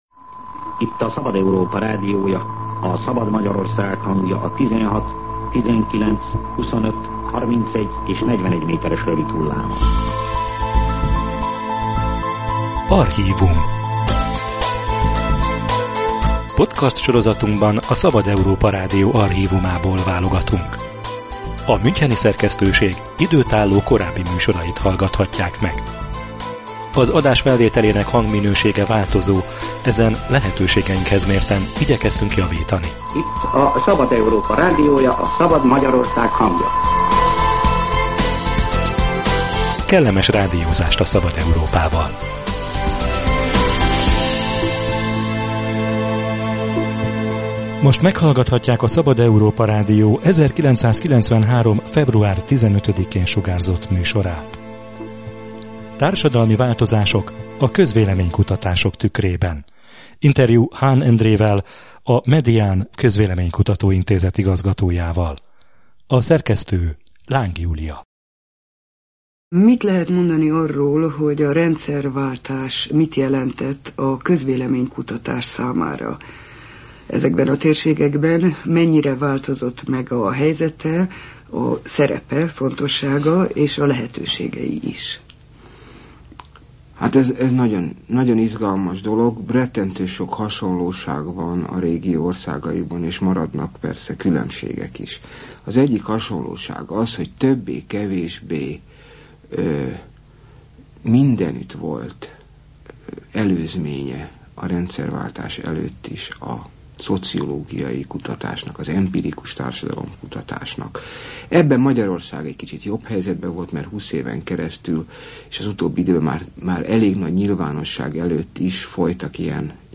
„Bonyolult játszma” – Archív beszélgetés a közvélemény-kutatások mai rendszerének születésekor